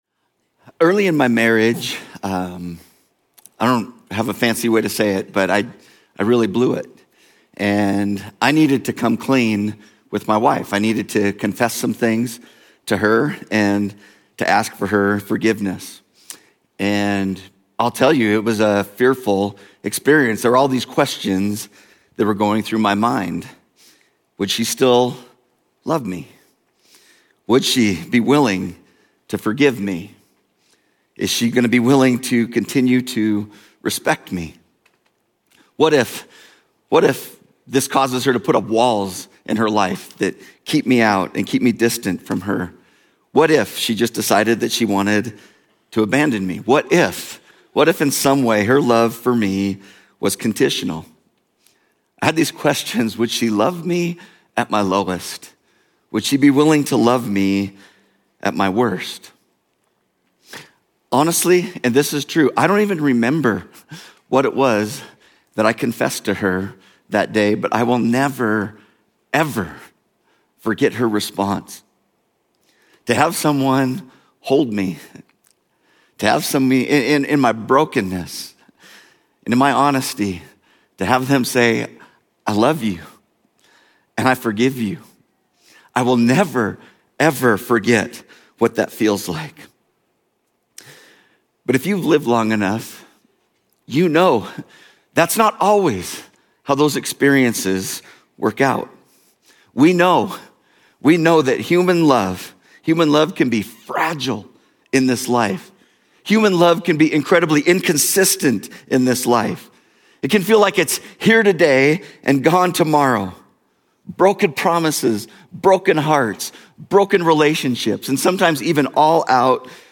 Journey Church Bozeman Sermons Book of Romans: Loved At Our Lowest Mar 02 2025 | 00:40:52 1x 00:00 / 00:40:52 Subscribe Share Apple Podcasts Overcast RSS Feed Share Link Embed